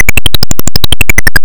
These sound effects are produced by the routine at 36307 when Willy is jumping.
jumping.ogg